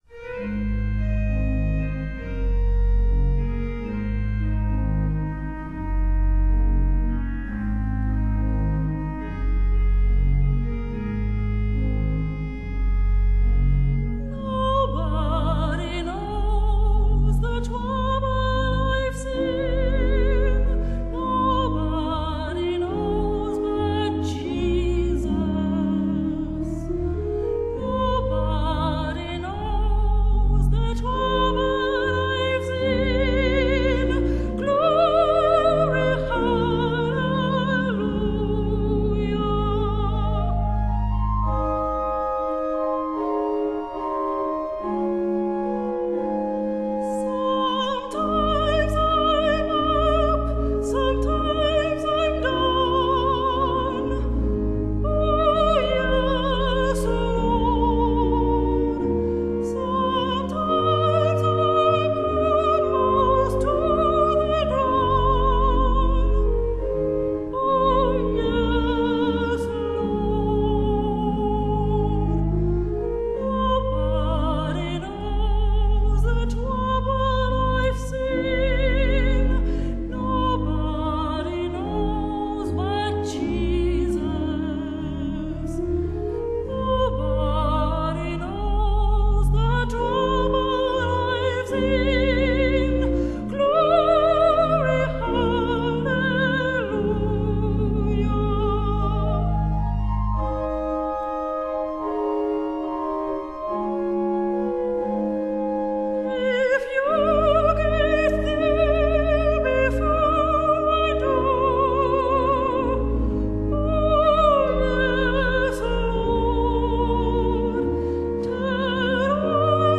Spiritual